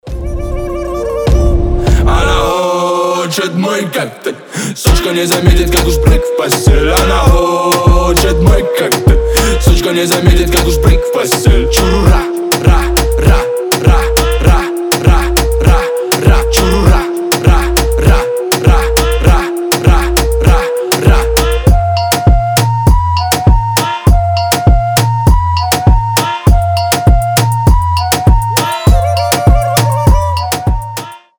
ритмичные
мощные басы
Trap
качающие
Moombahton
духовые
грубые